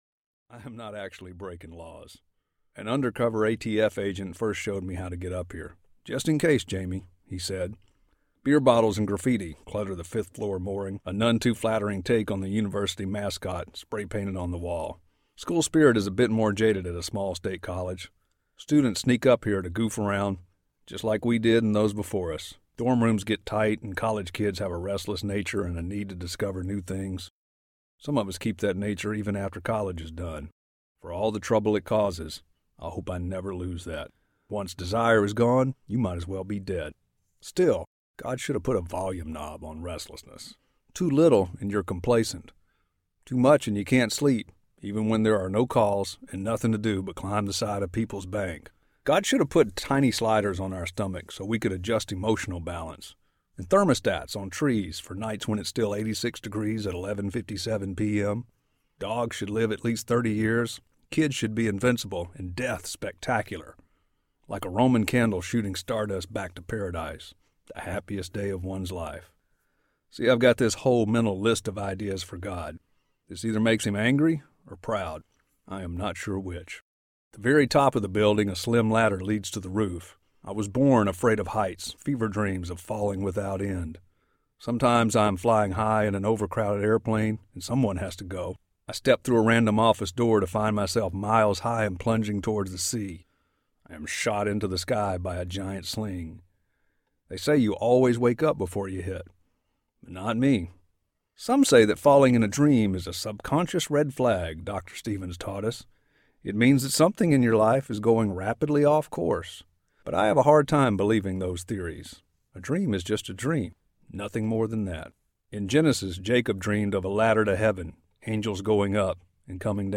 Mercy Never Sleeps Audiobook